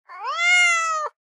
cat_tail_angry.ogg